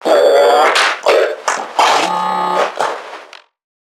NPC_Creatures_Vocalisations_Infected [126].wav